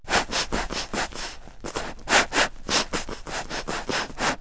/ cdmania.iso / sounds / illsnds / sniffing.wav ( .mp3 ) < prev next > Waveform Audio File Format | 1996-04-15 | 49KB | 1 channel | 22,050 sample rate | 4 seconds
sniffing.wav